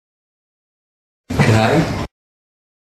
uitspraak Kraai